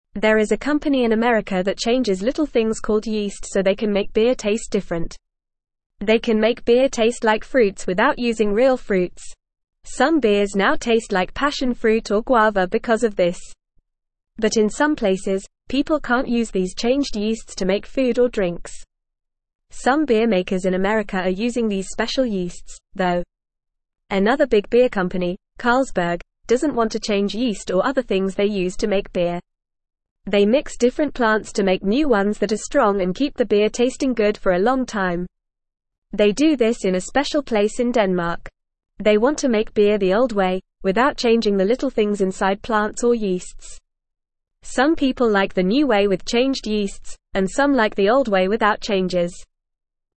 Fast
English-Newsroom-Beginner-FAST-Reading-Changing-Yeast-to-Make-Beer-Taste-Different.mp3